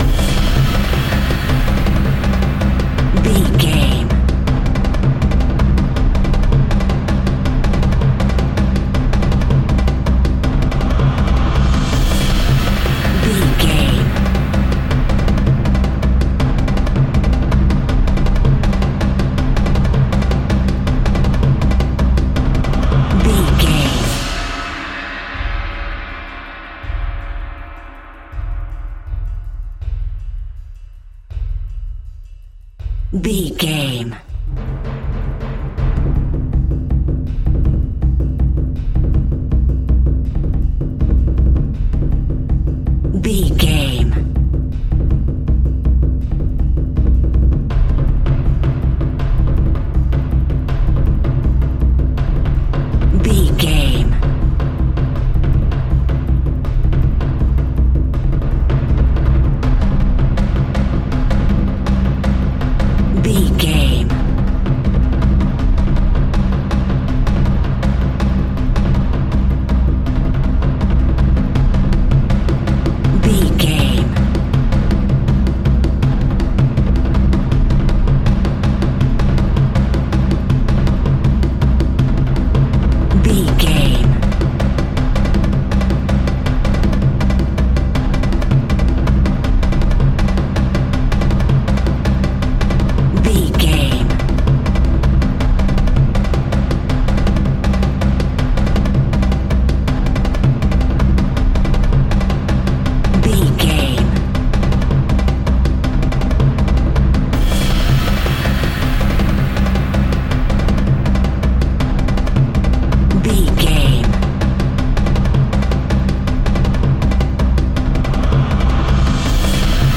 Epic / Action
Fast paced
Atonal
drum circle
World Music
percussion
bongos